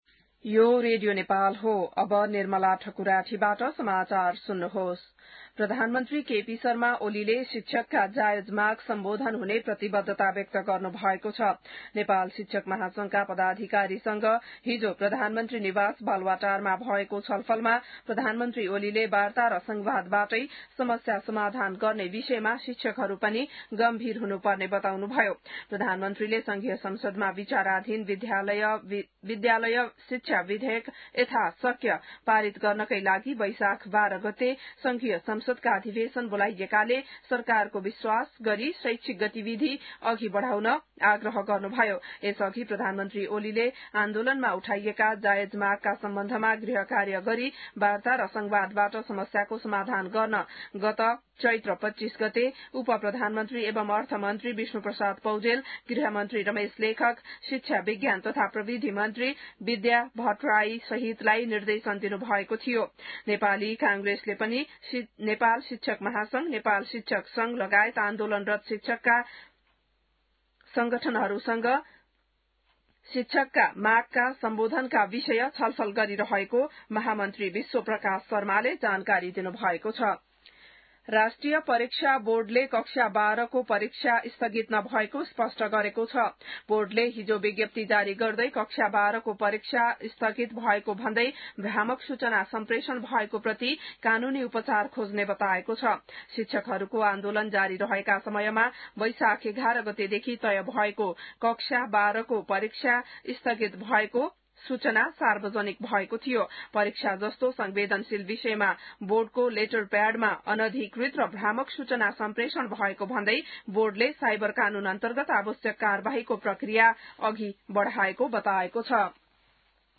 बिहान १० बजेको नेपाली समाचार : ६ वैशाख , २०८२